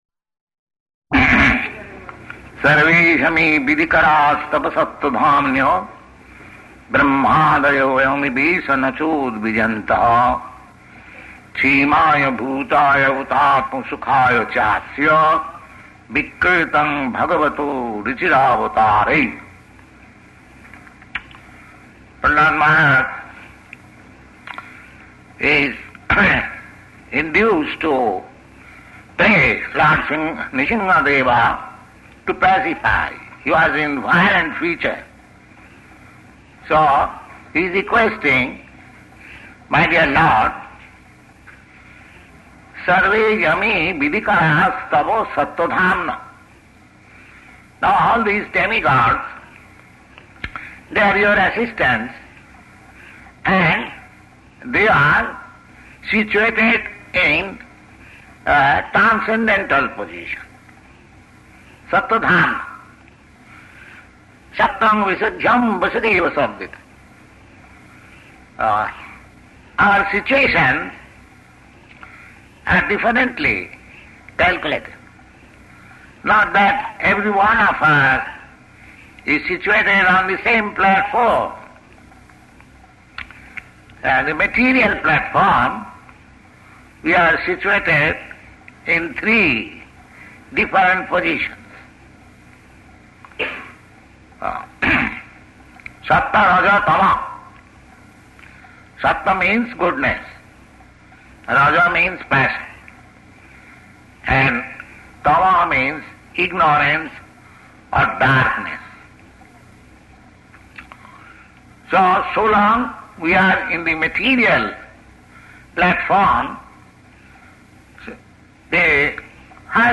Location: Montreal
[Warped audio]